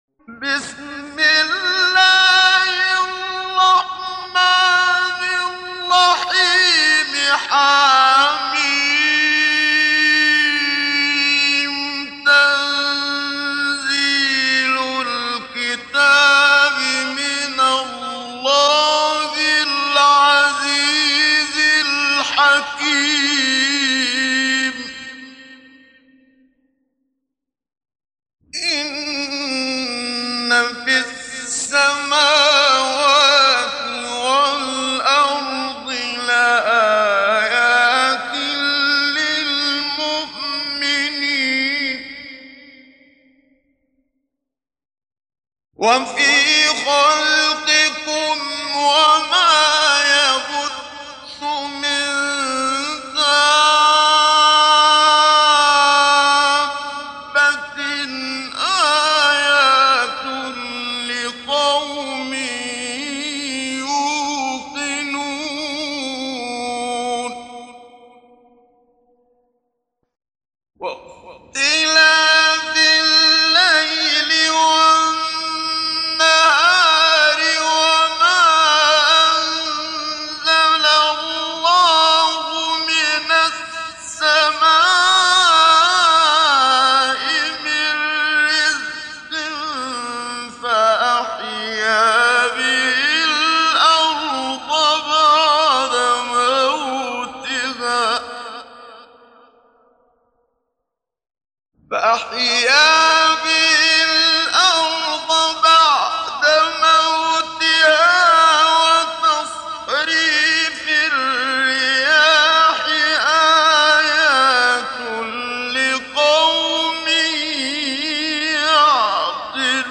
دانلود سوره الجاثيه mp3 محمد صديق المنشاوي مجود روایت حفص از عاصم, قرآن را دانلود کنید و گوش کن mp3 ، لینک مستقیم کامل
دانلود سوره الجاثيه محمد صديق المنشاوي مجود